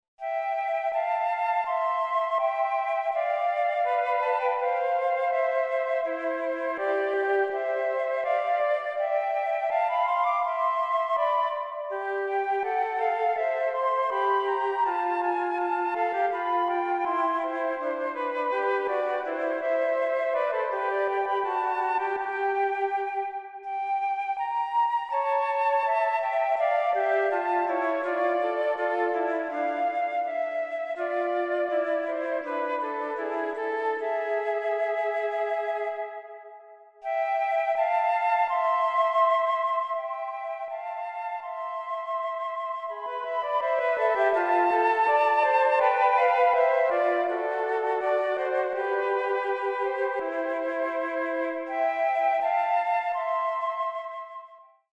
für 2 Flöten
Allegro